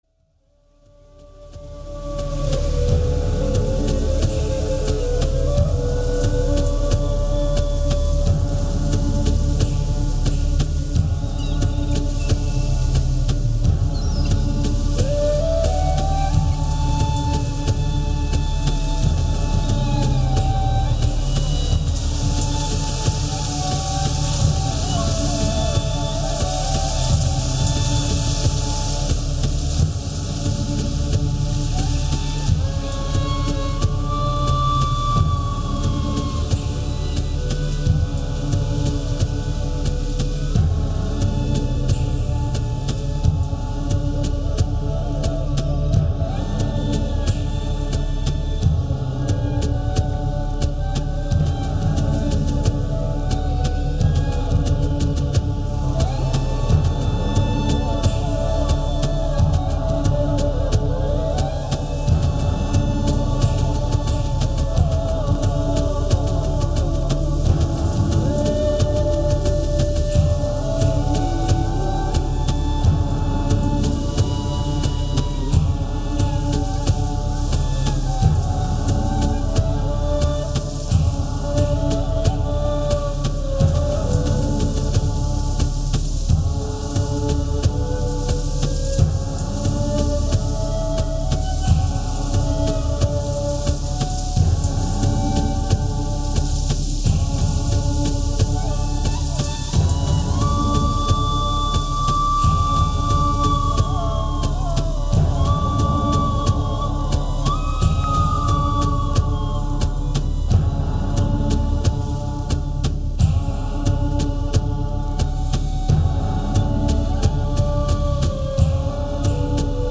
Genre: World Fusion.